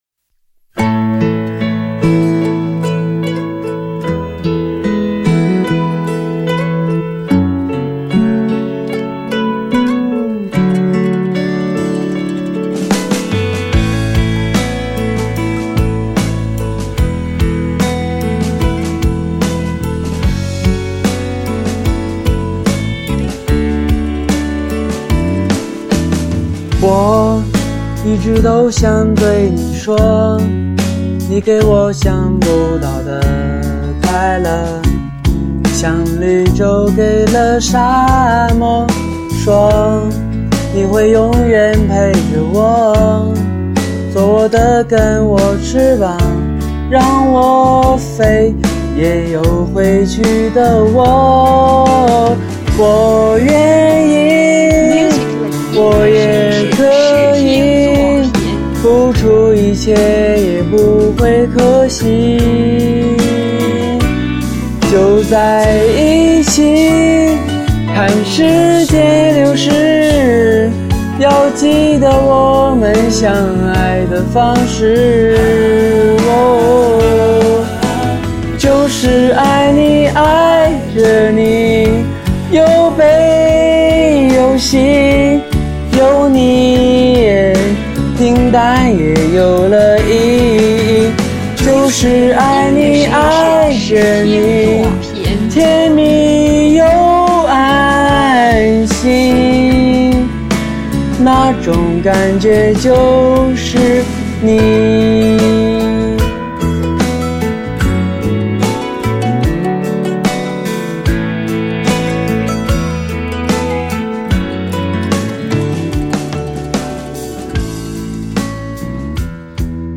这首是对口型的曲目，整个录音十分的糟糕，节奏音准都很糟糕，且偏差明显。